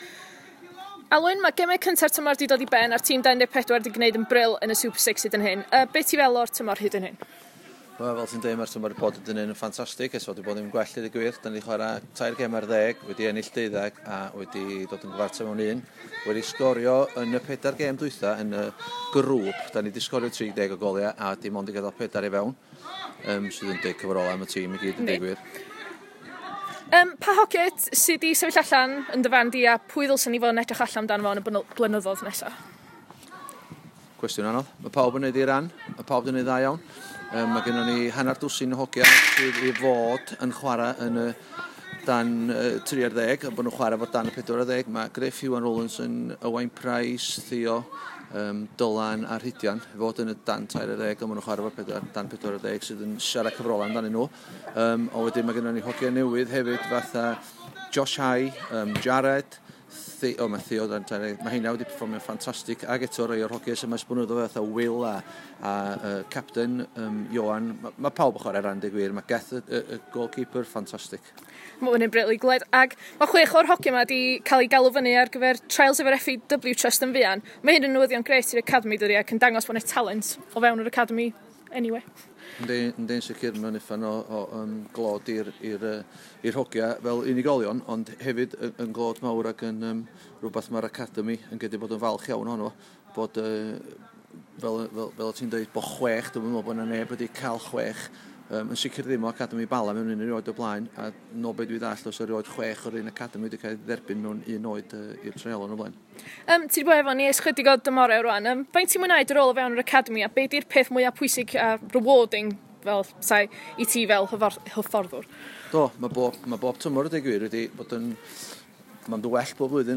Sgwrs